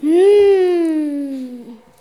hum_appetissant_01.wav